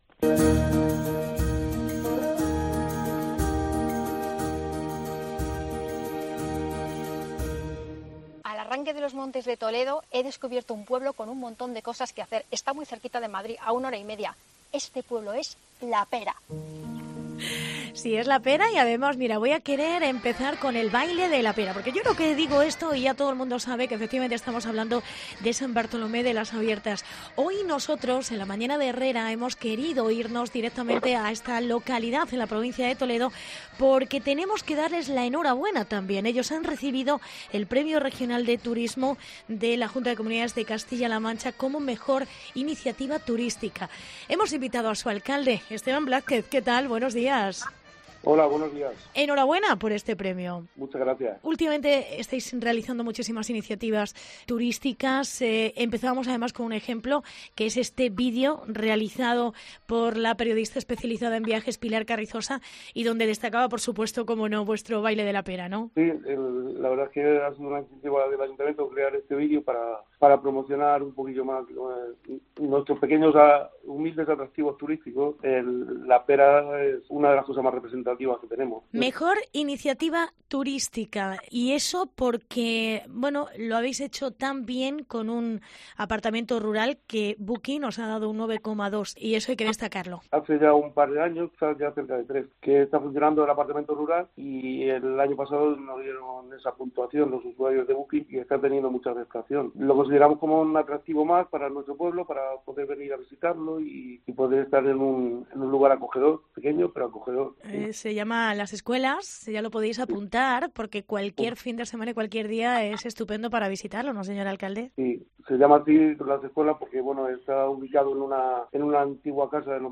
Entrevista con Esteban Blázquez. Alcalde de San Bartolomé de las Abiertas